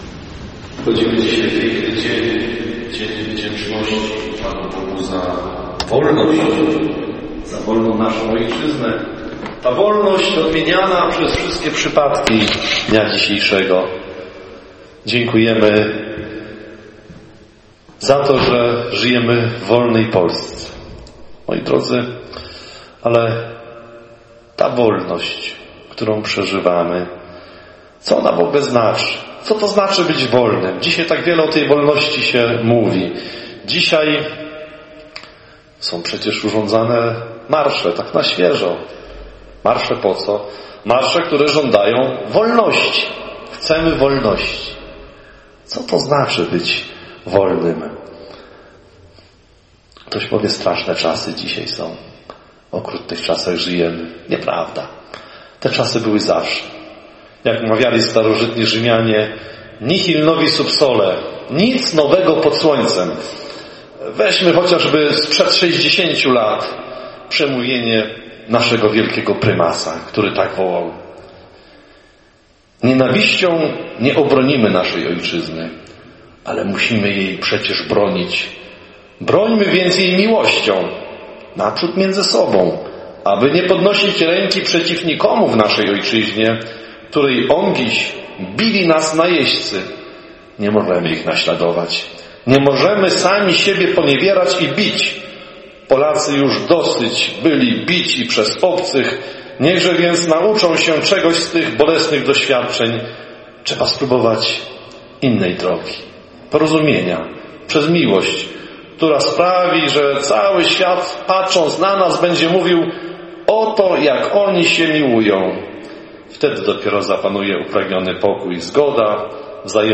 Msza-za-Ojczyznę-mp3cut.net_.mp3